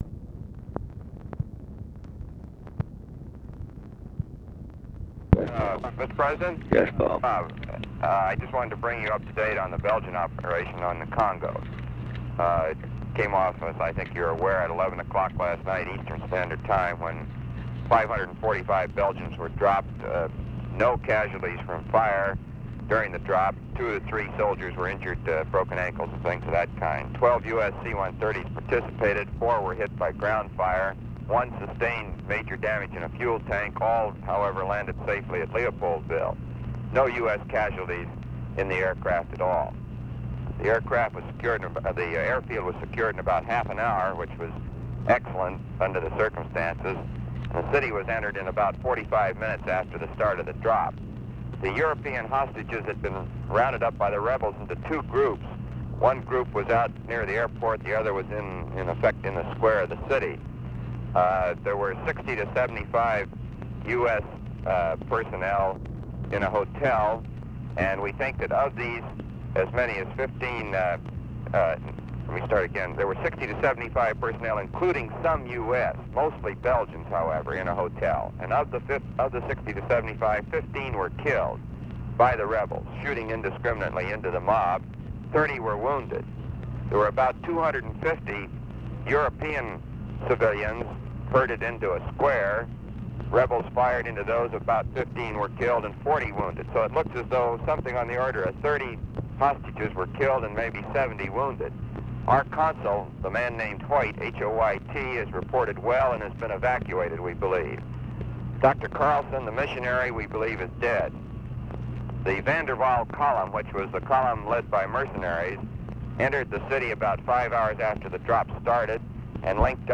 Conversation with ROBERT MCNAMARA, November 24, 1964
Secret White House Tapes